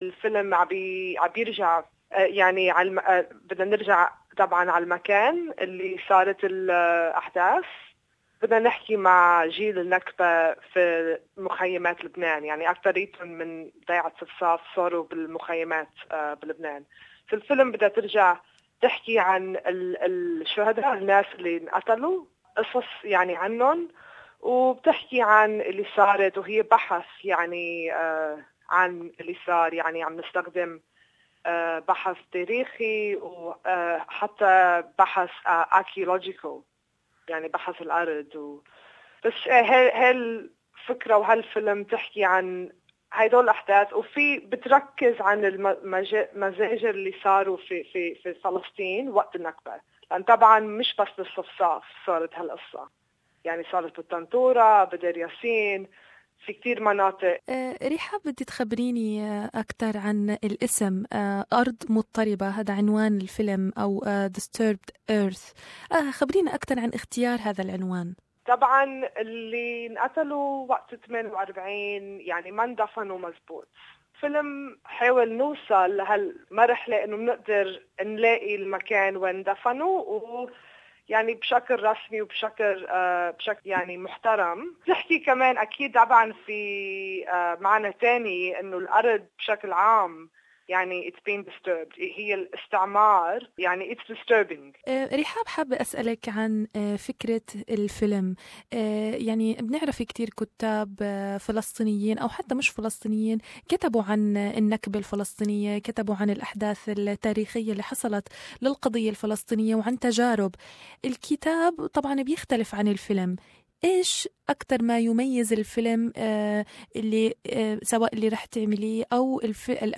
تتحدث عن الفيلم بالتفصيل في هذه المقابلة